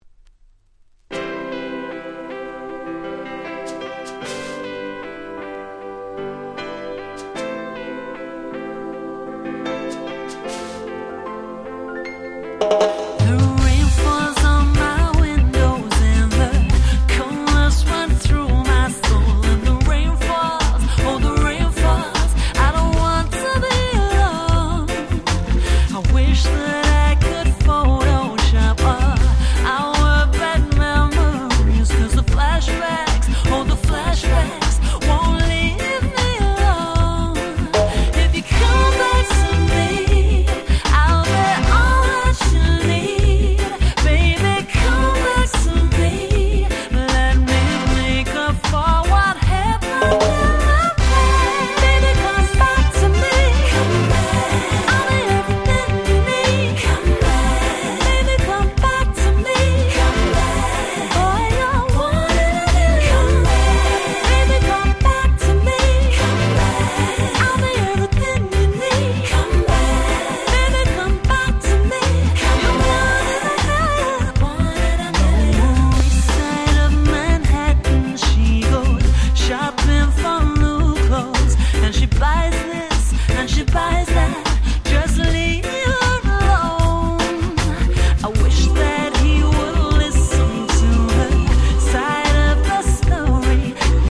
08' Super Nice Cover Reggae !!